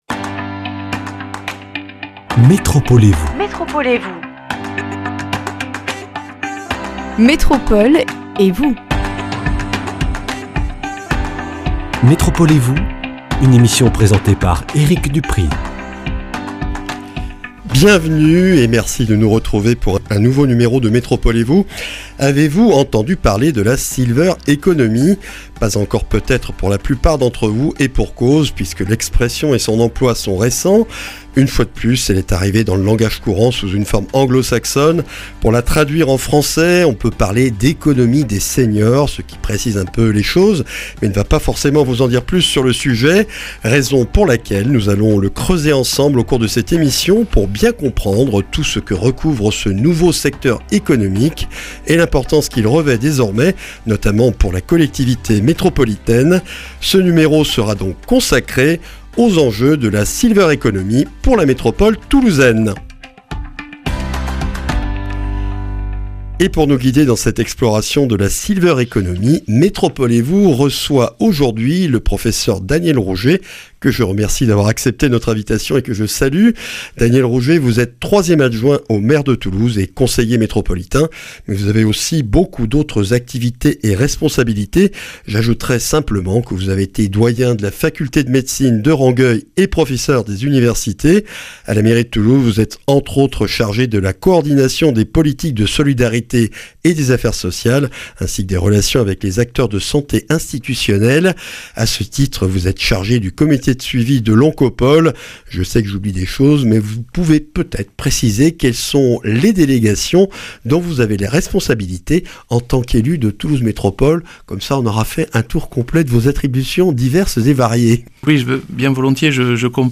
Rencontre et échange avec le professeur Daniel Rougé, 3ème adjoint au maire de Toulouse et conseiller métropolitain, pour une exploration du secteur de la Silver Économie afin de prendre la mesure des enjeux de "l’économie des seniors" pour la métropole toulousaine.